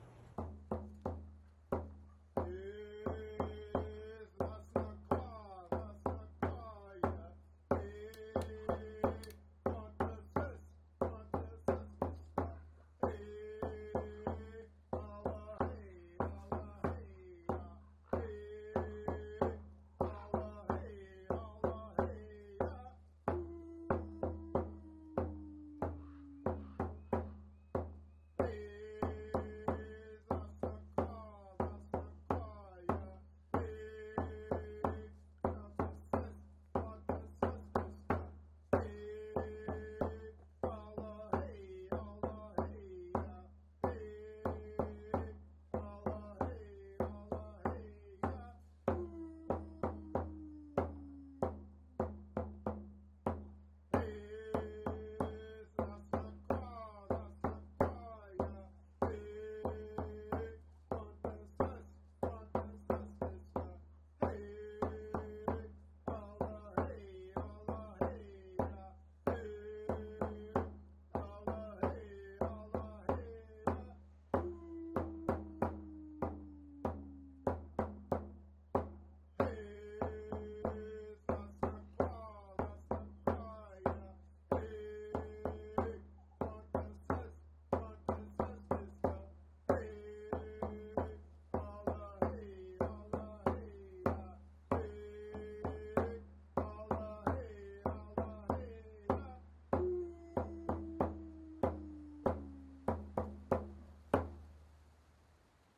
Click here to listen to Chief Planes sing “SṈE₭E” (“The Watchman”).11
11 The audio recording of Chief HYA-QUATCHA Gordon Planes performing “SṈE₭E” (“The Watchman”) is shared with permission from Chief HYA-QUATCHA Gordon Planes.